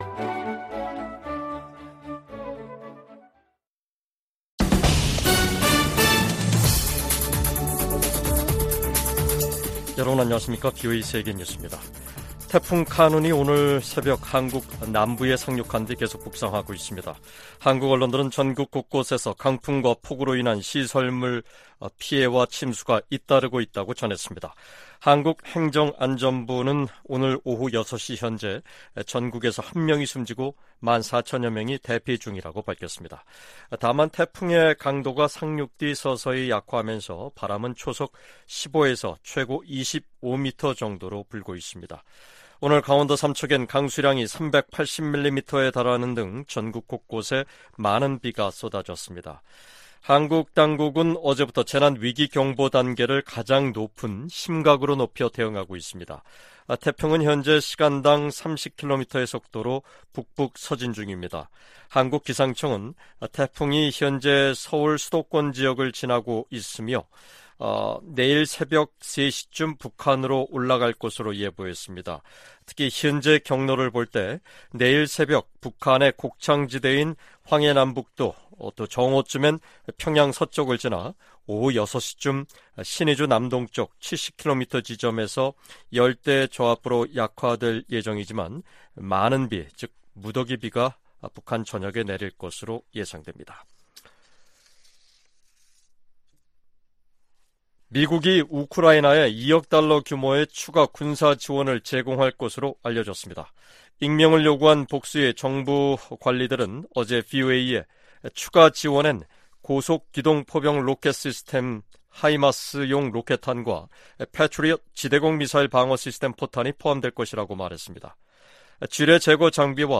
VOA 한국어 간판 뉴스 프로그램 '뉴스 투데이', 2023년 8월 10일 3부 방송입니다. 조 바이든 미국 대통령이 다음 주 열리는 미한일 정상회의에서 역사적인 논의를 고대하고 있다고 백악관 고위관리가 밝혔습니다. 미 국무부는 북한의 개성공단 무단 가동 정황과 관련해 기존 제재를 계속 이행할 것이라고 밝혔습니다. 김정은 북한 국무위원장이 '을지프리덤실드' 미한 연합연습을 앞두고 노동당 중앙군사위원회 확대회의를 열어 '공세적 전쟁 준비'를 강조했습니다.